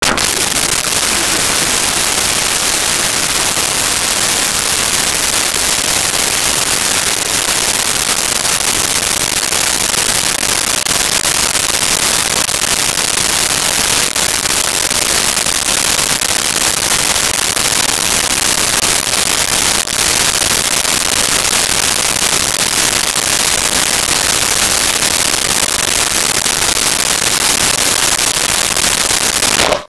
Toilet Flush 1
toilet-flush-1.wav